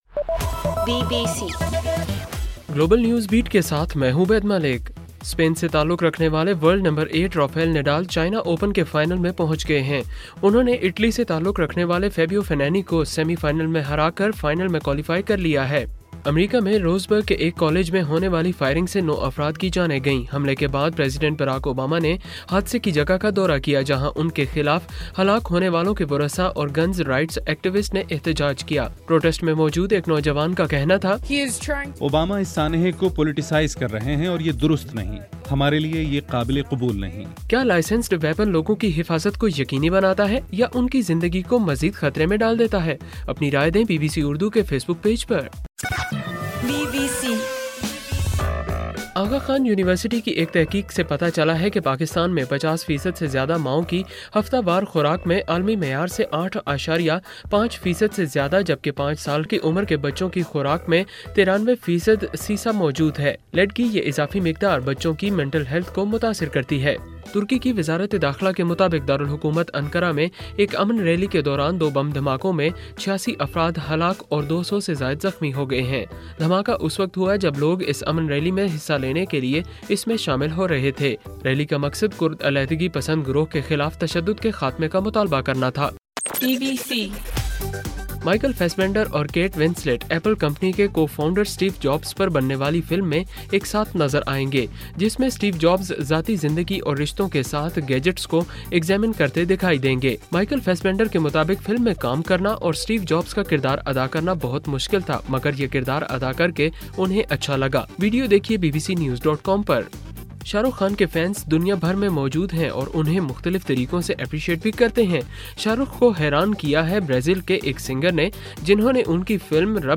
اکتوبر 10: رات 10 بجے کا گلوبل نیوز بیٹ بُلیٹن